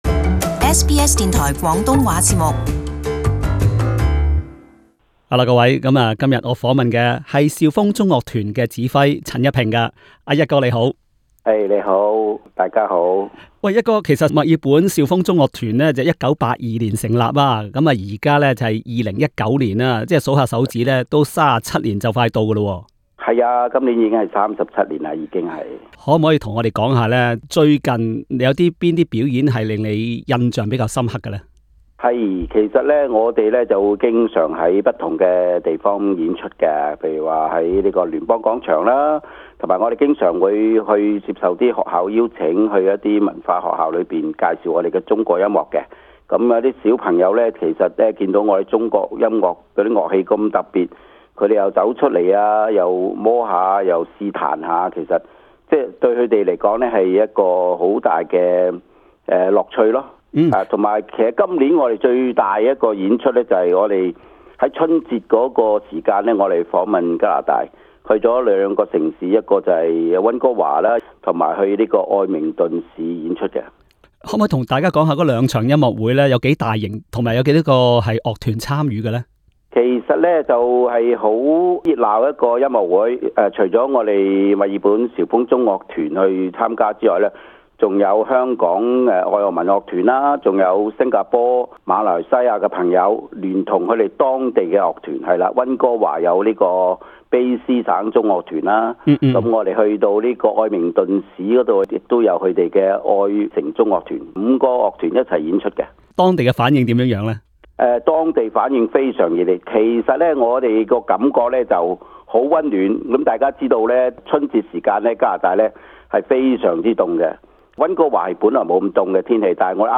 【社區專訪】肇風週年音樂會年輕人挑大樑 09:27 肇風團員溫哥華維多利亞精選一天遊，在雪地拍照留念。